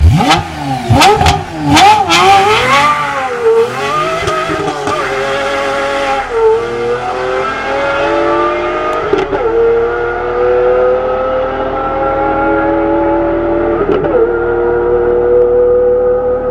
pagani-zonda-f_24863.mp3